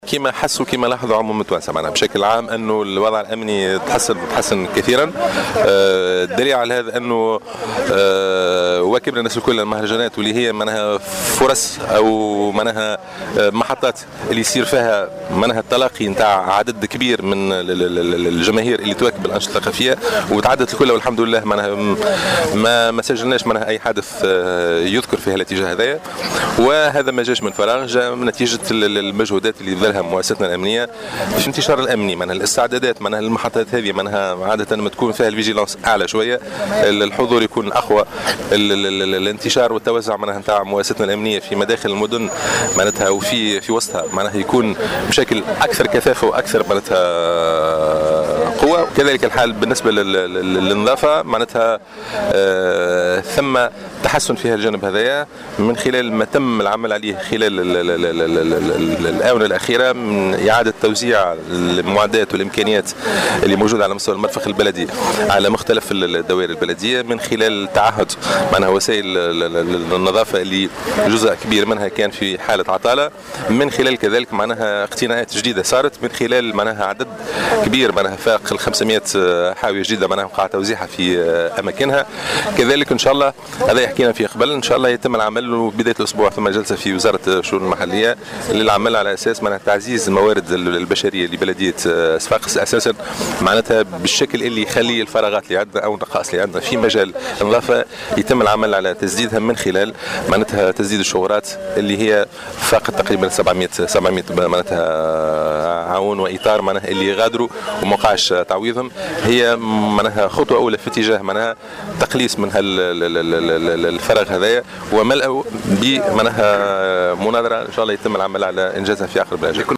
وأوضح اليوم في تصريح لمراسل "الجوهرة أف أم" على هامش تدشين نافورة وسط المدينة تم انجازها بكلفة 350 ألف دينار، أنه تم إعادة توزيع المعدات على مختلف الدوائر البلدية مع القيام باقتناءات جديدة (أكثر من 500 حاوية جديدة)، إضافة إلى تعزيز الموارد البشرية وفتح مناظرة قريبا لسدّ الشغورات التي فاقت 700 عونا وإطارا، وفق تعبيره.